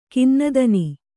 ♪ kinnadani